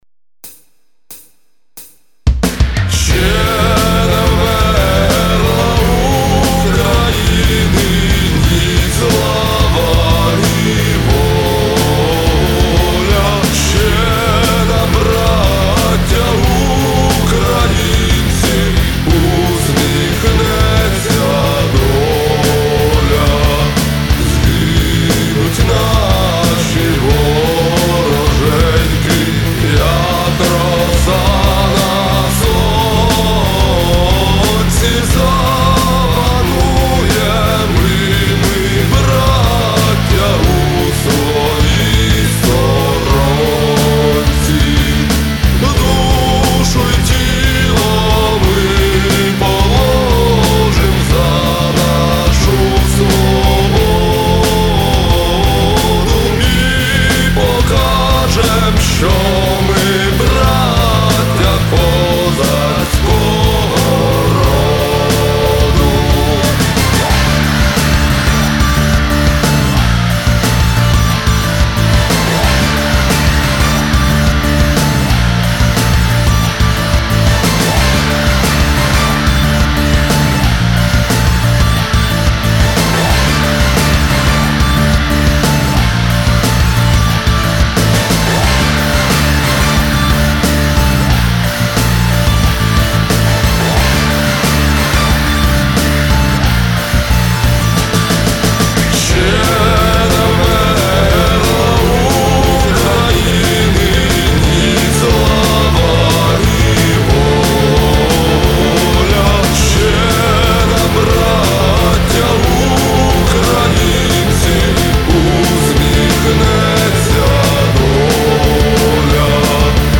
gimn_ukraini_rok_versija___so_slovami_.mp3